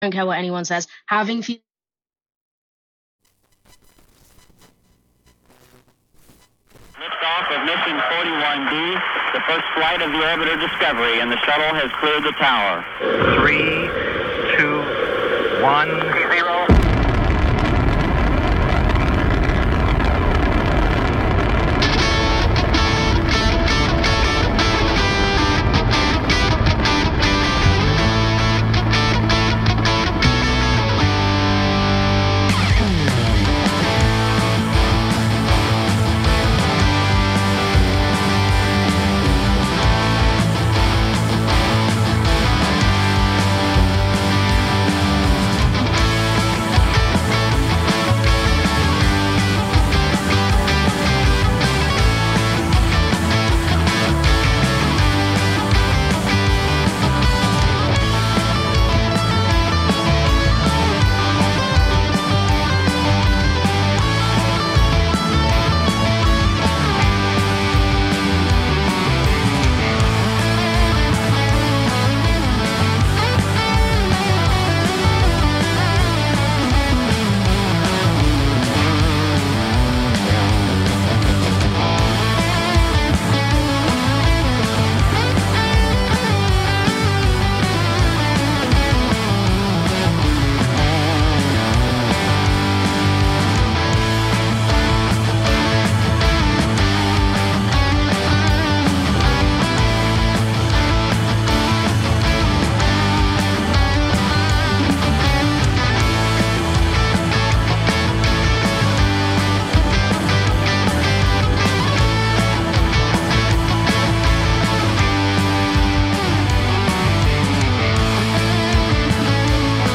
Programa con la mejor musica rock